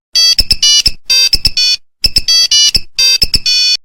14. Старый телефон мелодия пищалка